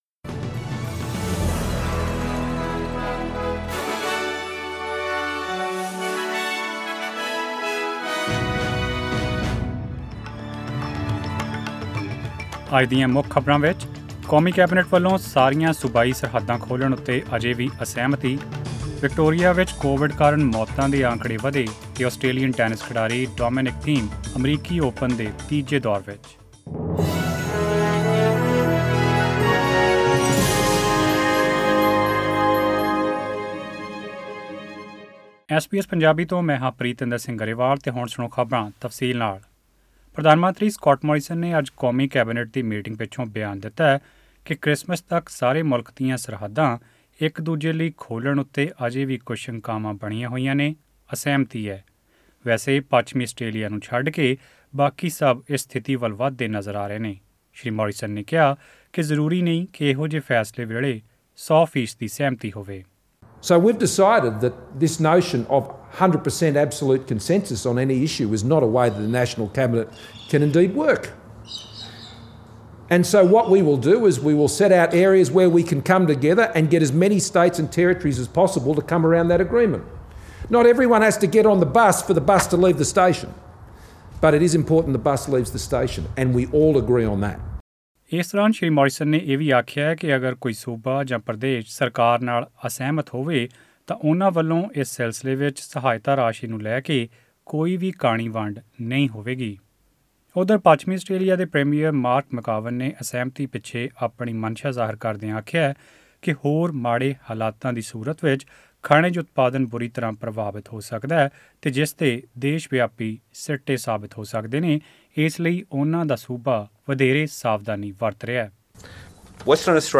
In this bulletin... The national Cabinet fails to reach agreement on COVId-19 border restrictions.